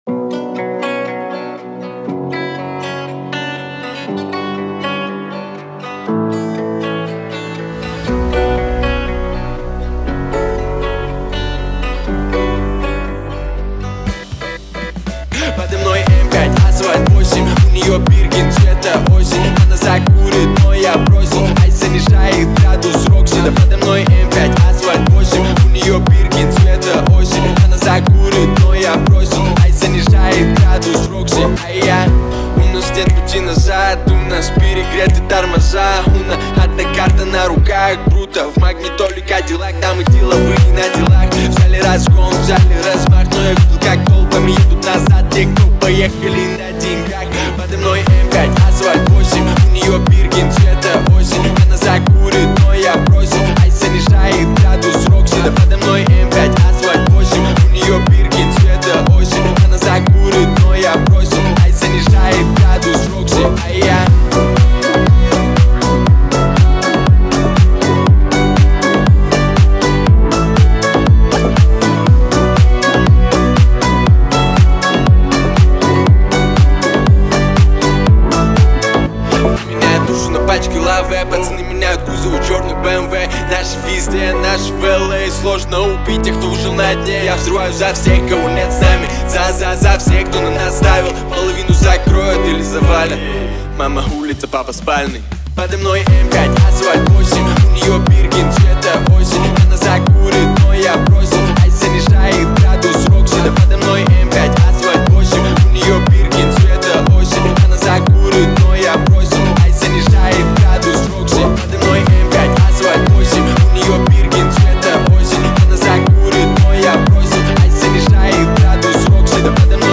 • Качество: 320 kbps, Stereo
тик ток ремикс